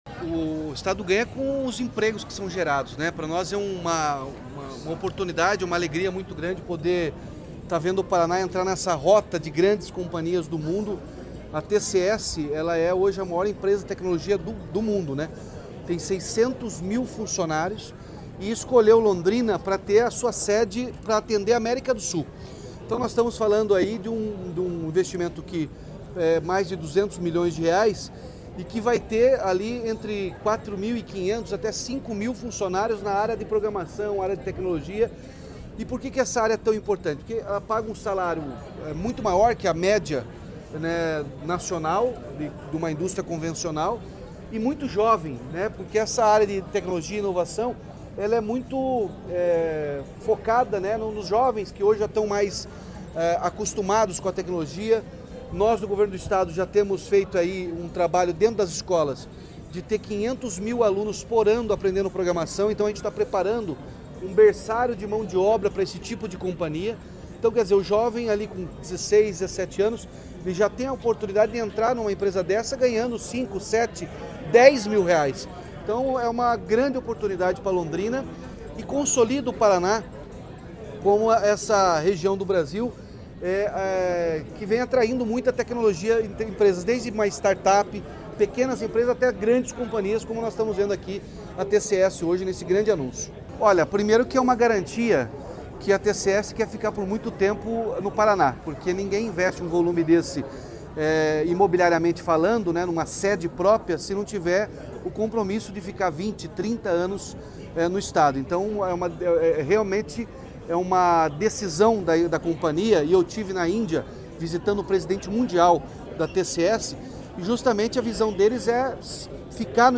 Sonora do governador Ratinho Junior sobre o anúncio de novo campus da TCS em Londrina